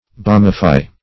Balmify \Balm"i*fy\